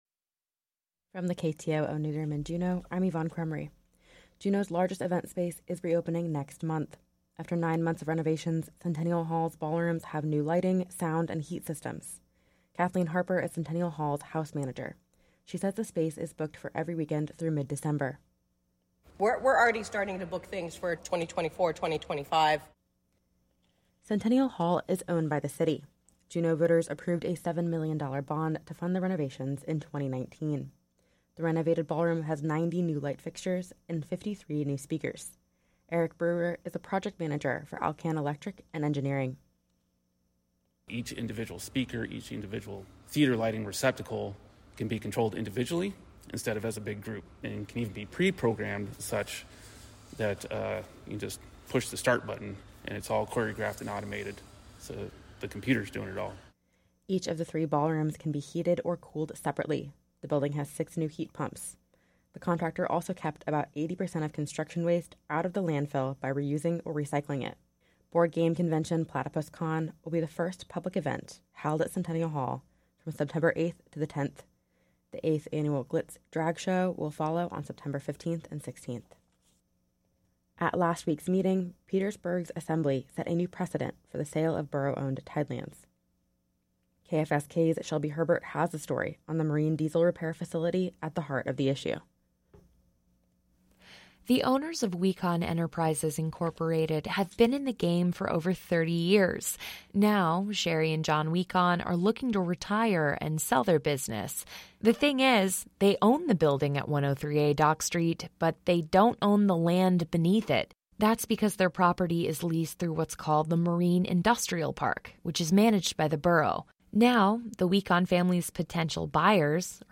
Newscast – Monday, August 28, 2023